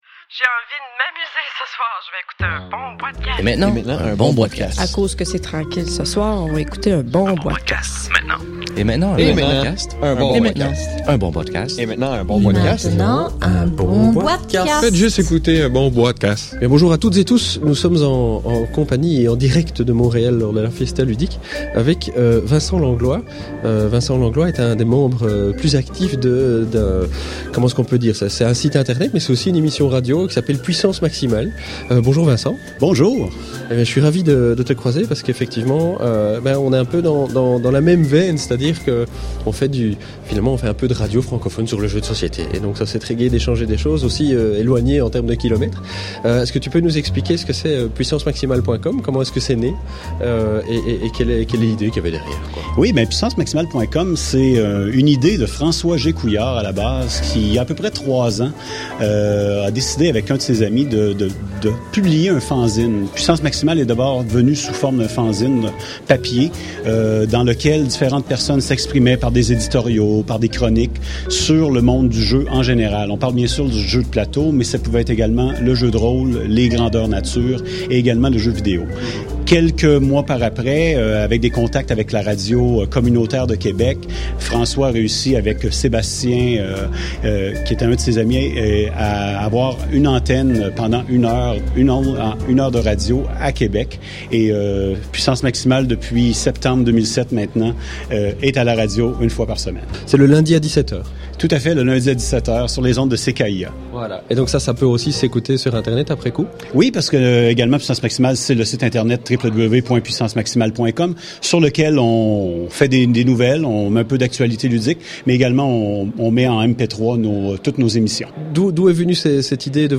enregistré lors de la Fiesta Ludiq de Montreal 2009